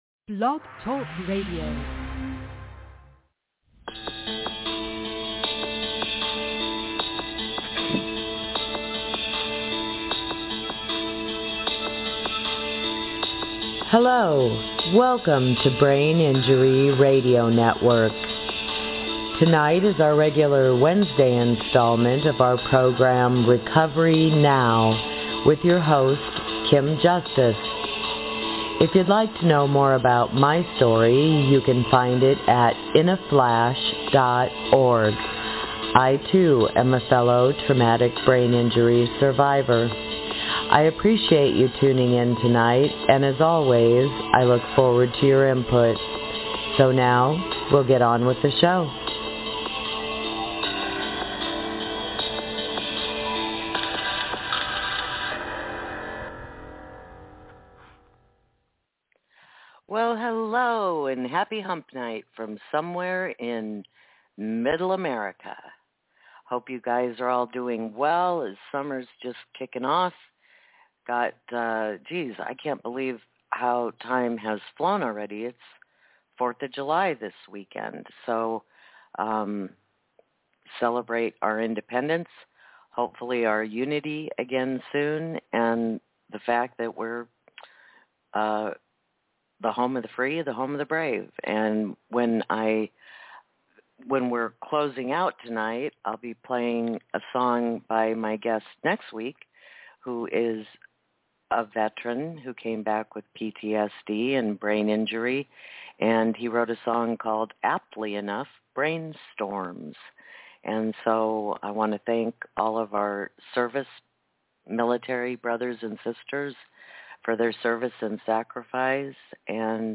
Tonight is OPEN MIC – I haven’t been on social media much, gone cross eyed on Zoom, haven’t lifted the 100 pound phone, and have been laying low.
Had a guest cancellation/reschedule tonight, and decided rather than putting in a rerun .. I would show up for what the network was designed for .. Live support.